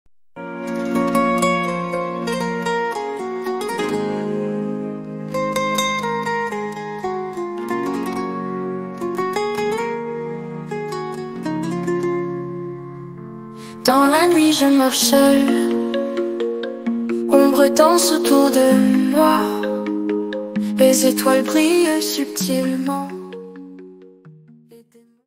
Style : Trance